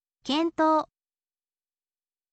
kentou